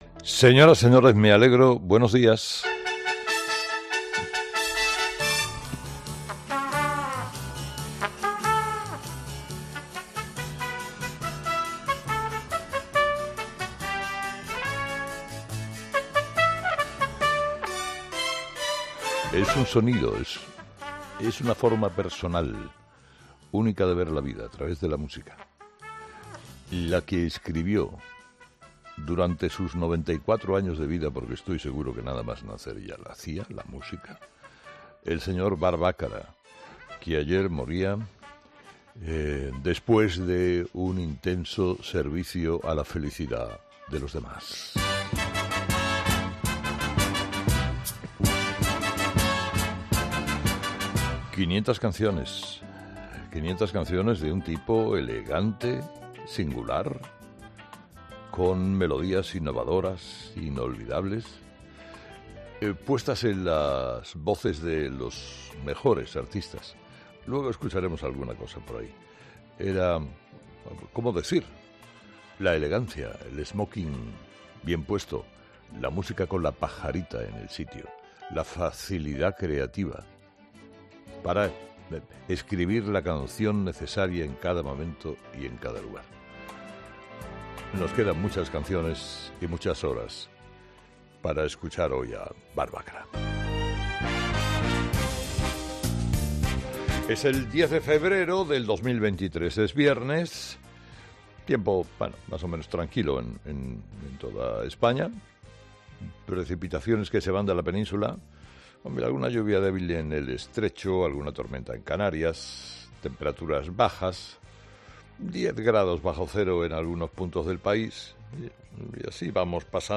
Carlos Herrera, director y presentador de 'Herrera en COPE', comienza el programa de este viernes analizando las principales claves de la jornada, que pasan, entre otros asuntos, por la decisión del Tribunal Constitucional avalando la ley del aborto que se tomó este jueves.